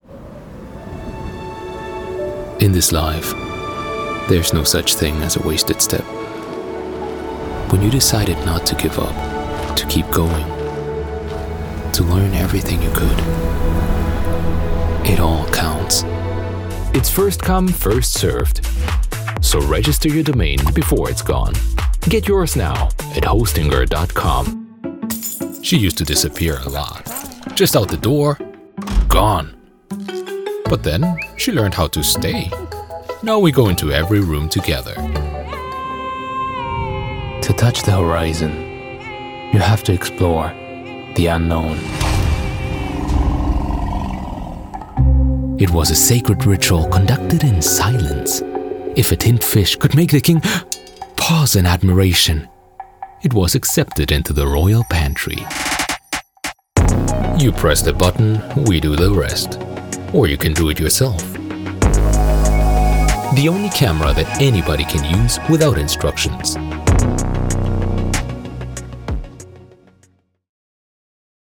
Kommerzielle Demo
Junger Erwachsener
Im mittleren Alter
NatürlichLustigWarmKonversationFesselnd